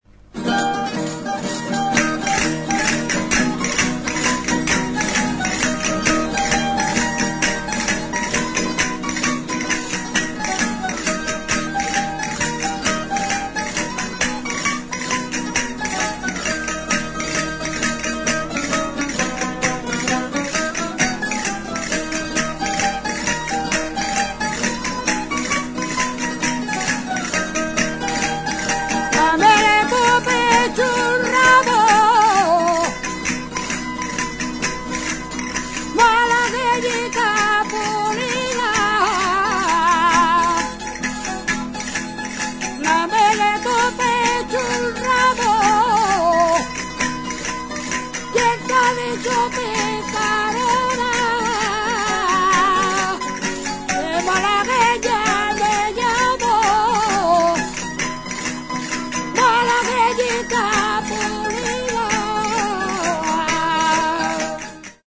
Idiophones -> Struck -> Directly
Idiophones -> Scraped
(Membrilla, Ciudad Real).
Soka batekin muturretatik segidan lotutako 10 hezur dira. Zurezko goilare bikoitzarekin dator.